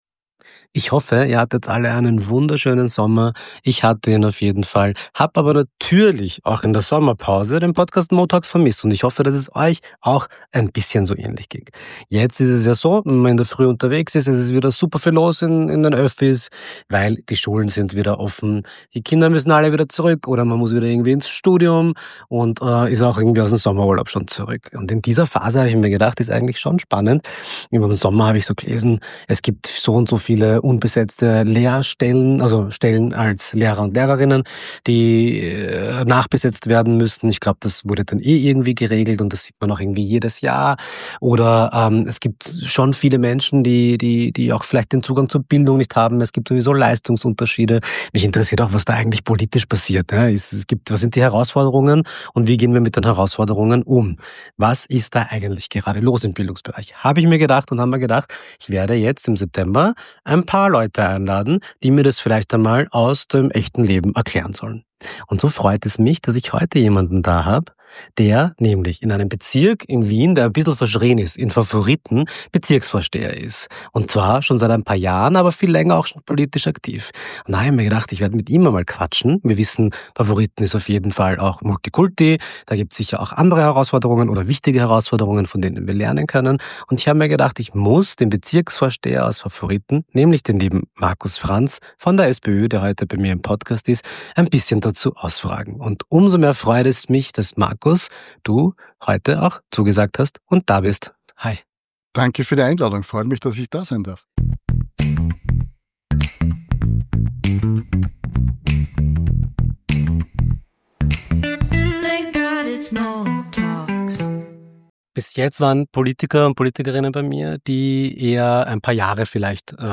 Direkt zum Schulbeginn Anfang September war er bei mir im Podcast zu Gast, um über die Herausforderungen – aber auch die Chancen – im Bildungssystem zu sprechen.